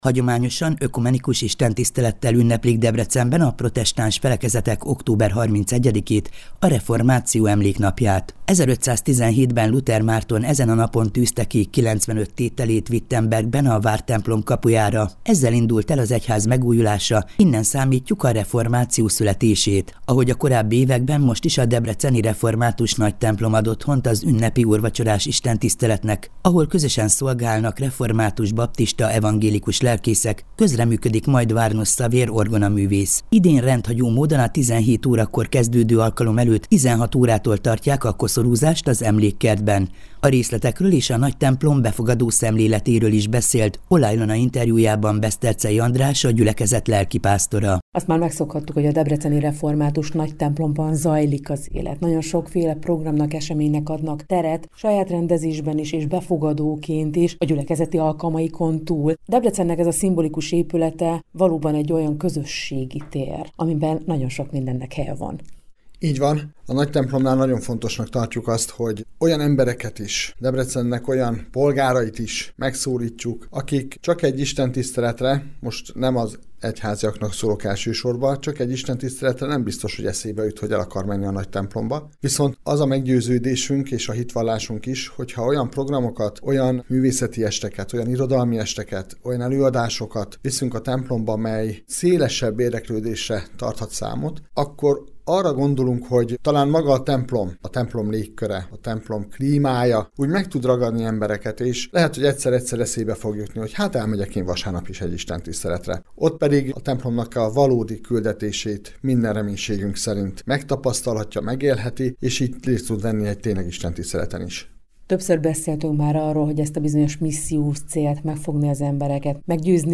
Hagyományosan ökumenikus istentisztelettel ünneplik Debrecenben a protestáns felekezetek október 31-ét, a Reformáció Emléknapját. 1517-ben Luther Márton ezen a napon tűzte ki 95 tételét Wittembergben a vártemplom kapujára, ezzel indult el az egyház megújulása, innen számítjuk a reformáció születését.